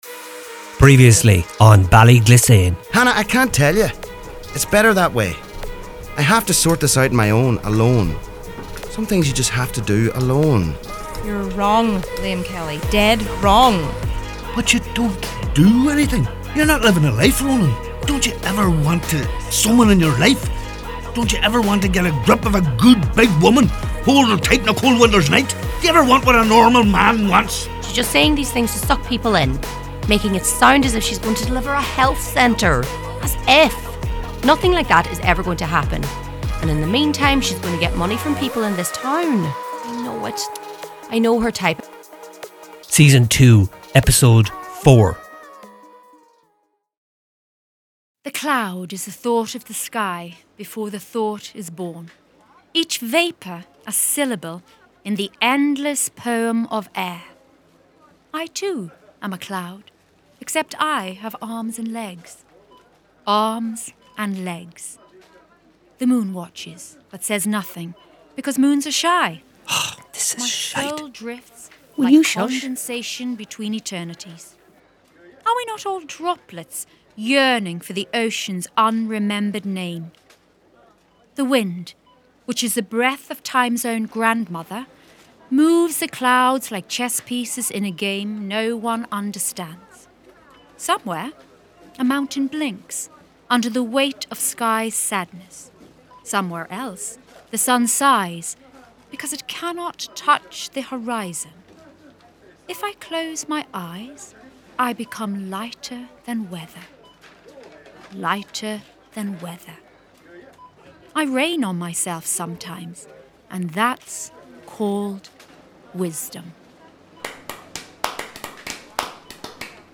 A drama series set in a small town somewhere in the heart of Donegal, where we meet various people who live in Ballyglissane and learn about their lives, their problems, and their secrets.